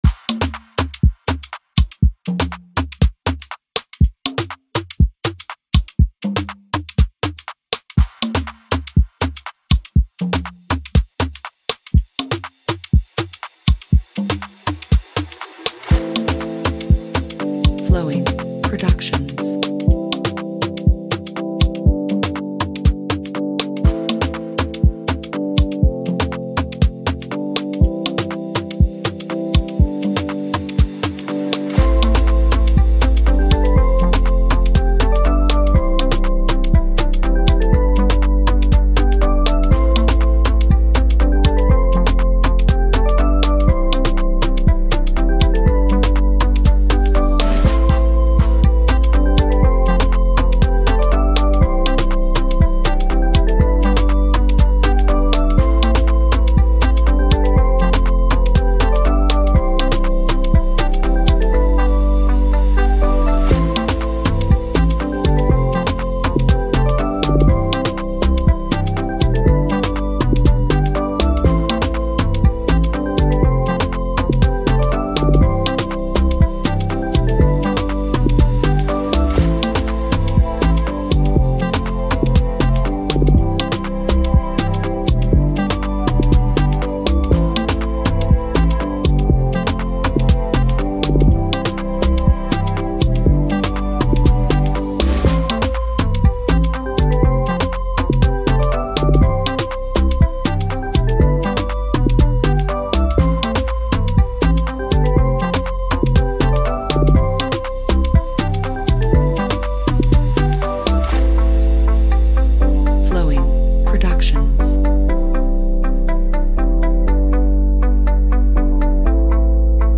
is a vibrant music tune
Amapiano songs